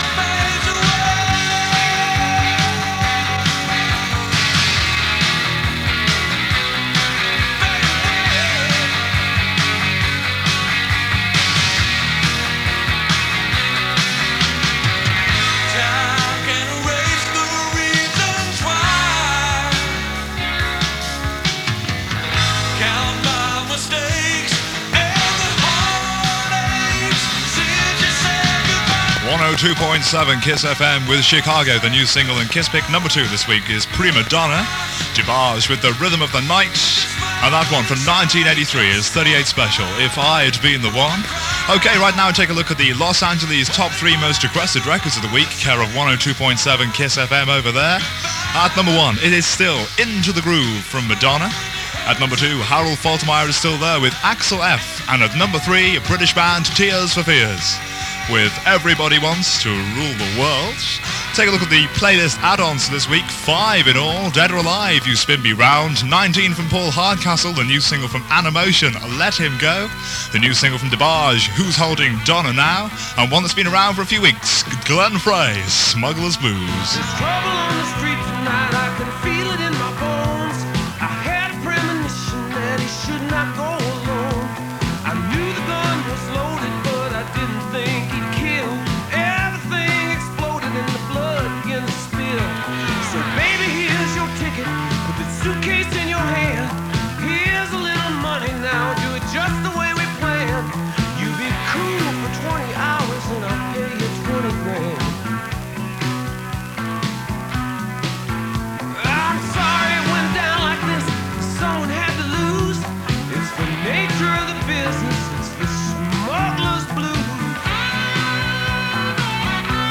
The crew come on live at the end, from the transmission site. Sadly the tape runs out before the 'chatter' finishes. Recorded near Blackpool from around 102.9MHz. There are one or two bits of co-channel interference, possibly from summer FM conditions. 65MB 47mins